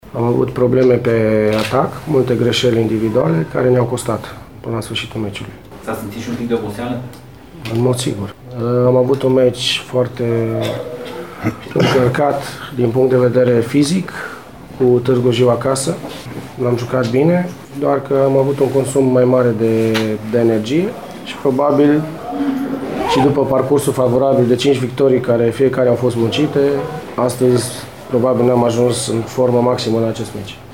Declaraţii după meci: